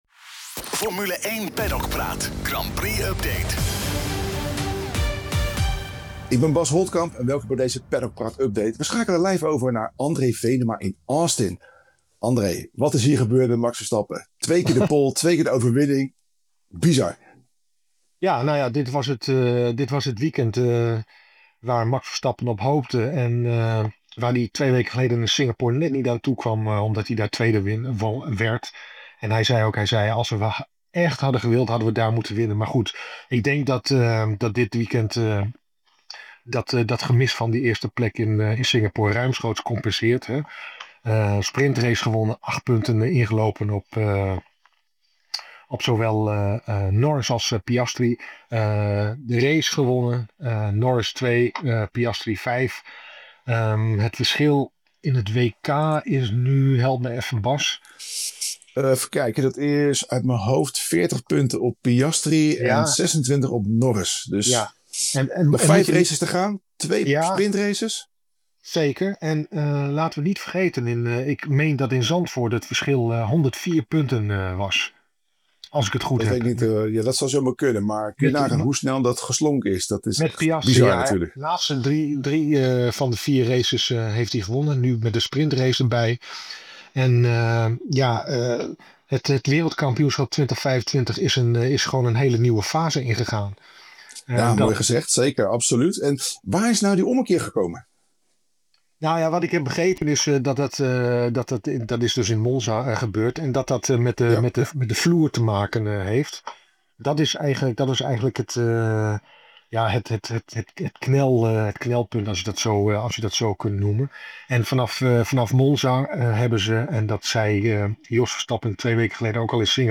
blikken we vanaf het Circuit of the Americas terug op de Grand Prix van de Verenigde Staten. Max Verstappen hield op weergaloze wijze huis in Austin: pole voor de sprintrace, pole voor de Grand Prix, én winst op beide dagen.